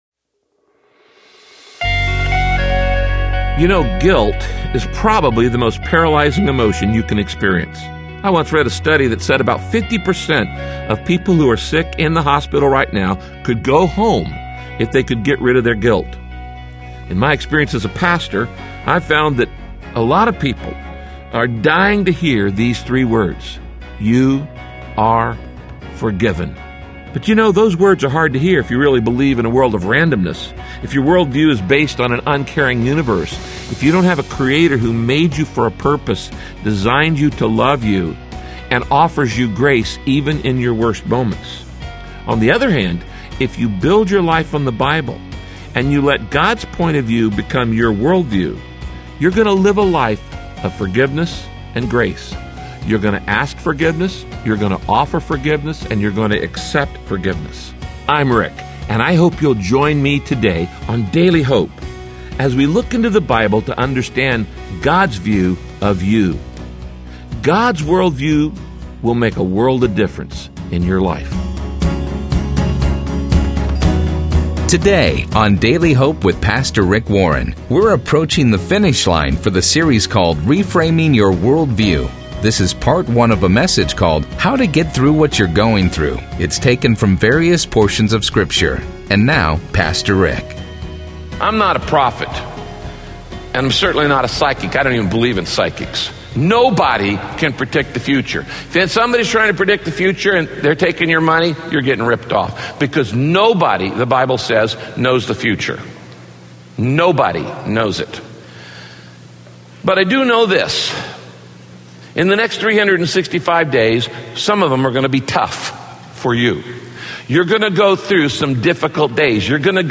Pastor Rick Warren returns to the pulpit